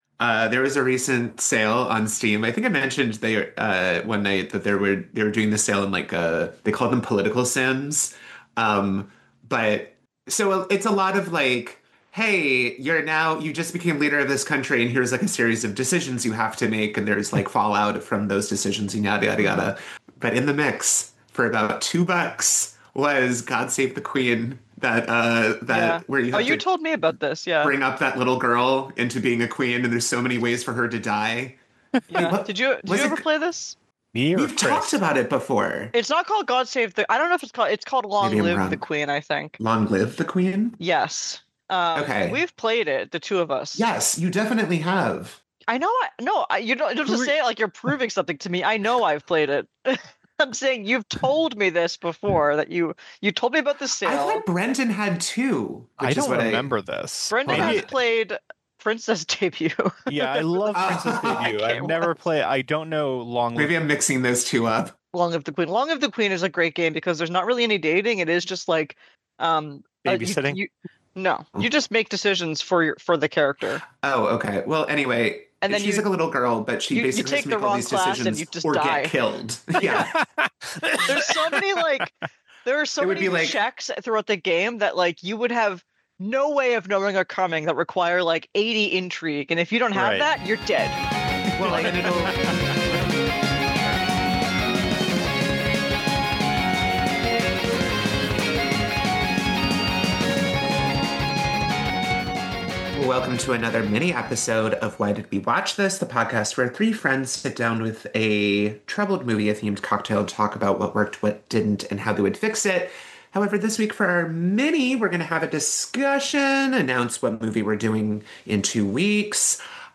Three friends praising, destroying, and fixing the messiest, crappiest movies known to humanity.